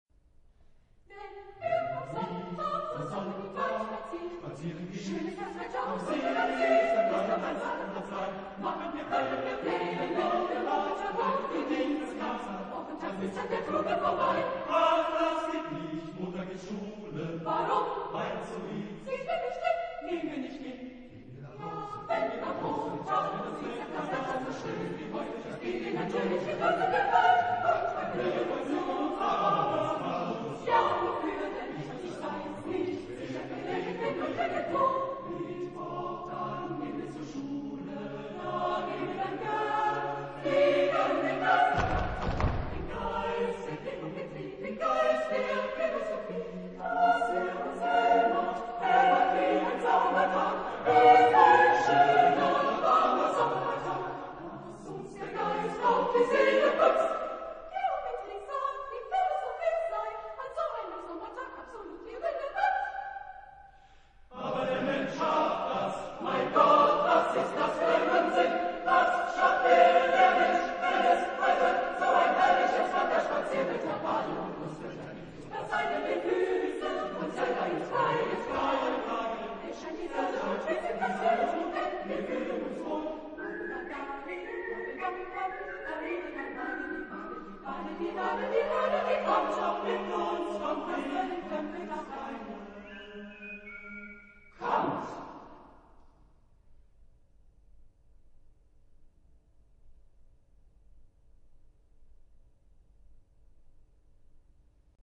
Genre-Style-Forme : contemporain ; Profane
Type de choeur : SATB  (4 voix mixtes )
Réf. discographique : 7. Deutschen Chorwettbewerb Kiel 2006